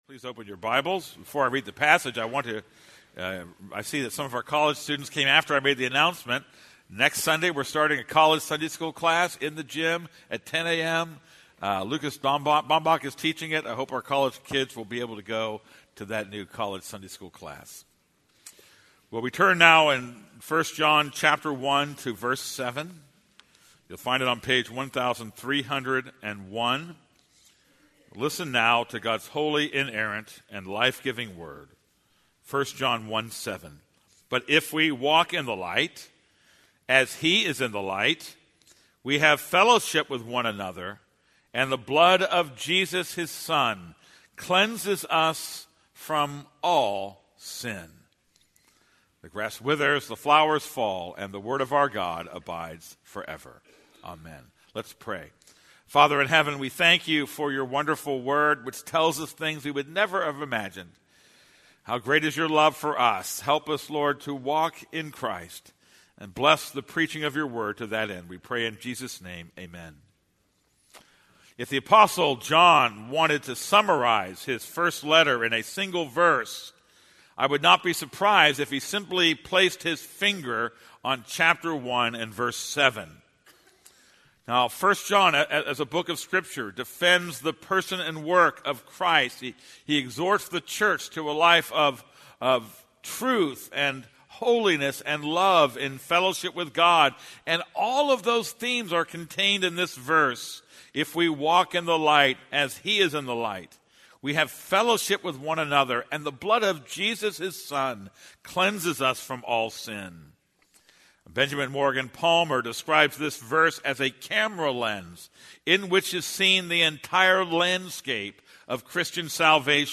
This is a sermon on 1 John 1:7.